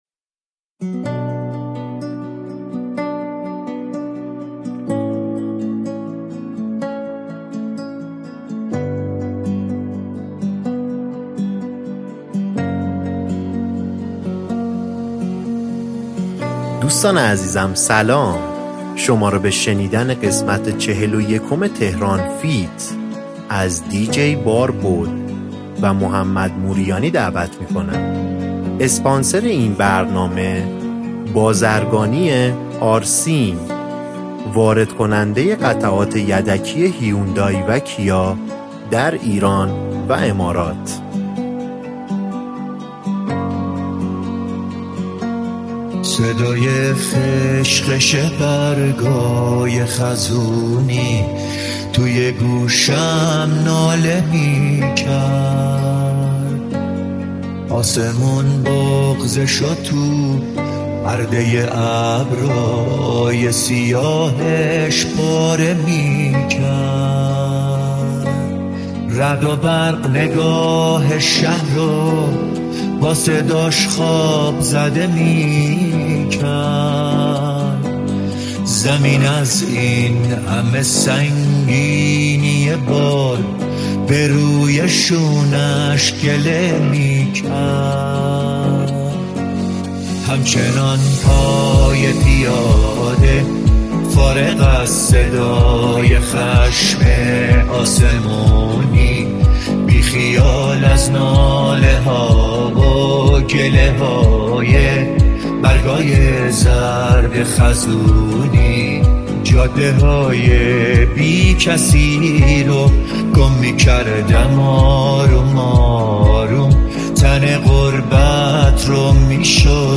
دانلود ریمیکس جدید و پرانرژی
ریمیکس پرانرژی